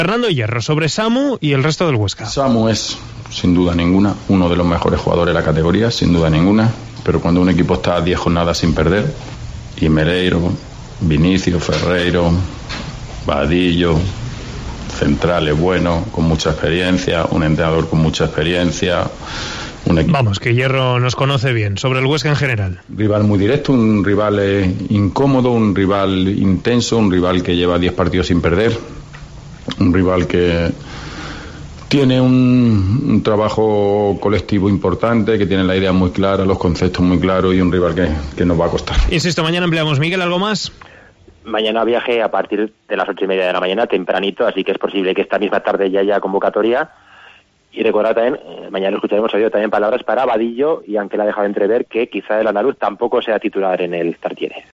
Escuchamos las declaraciones del entrenador del Real Oviedo antes de jugar este viernes contra la Sociedad Deportiva Huesca.